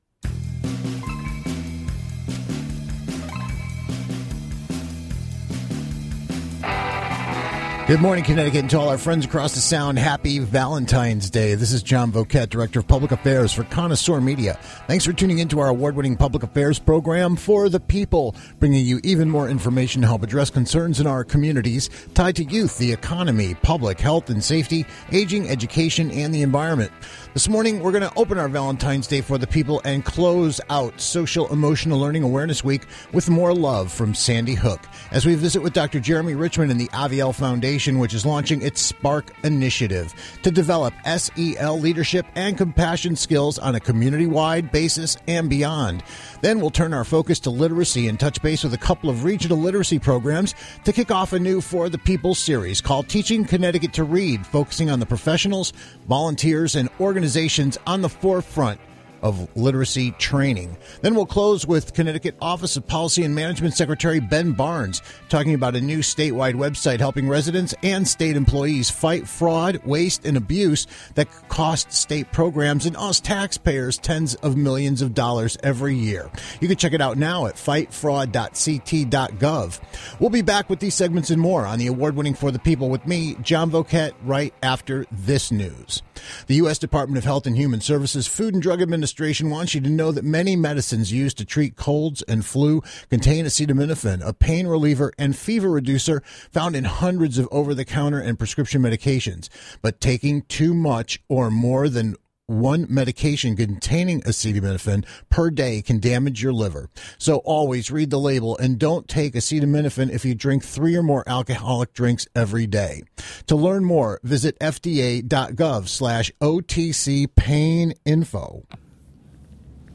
Then we'll learn about two very different literacy training organizations in the first segment of a new series called 'Teaching Connecticut To Read.' And we'll wrap replaying a chat with Connecticut Office of Policy & Management Secretary Ben Barnes discussing the launch of a new statewide fraud prevention website already saving taxpayers millions.